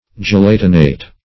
Gelatinate \Ge*lat"i*nate\, v. i.